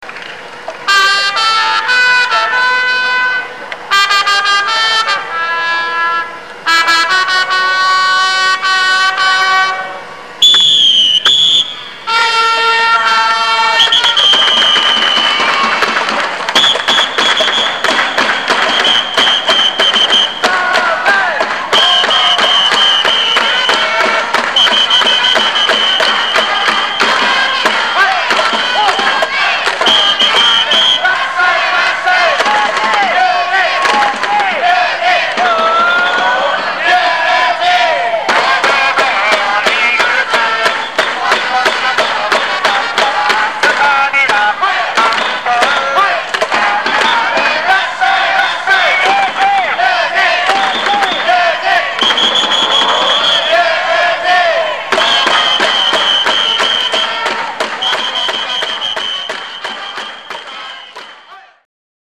生音声（mp3） MIDI
前奏：（ドンドンドドドン　ドンドンドドドン　ドンドンドドドン　ドンドンドンドン）
音源は関東のため、「オープニングテーマ」（青葉城恋唄の替え歌）が流れていますが、
メガホンの叩き方も特殊です。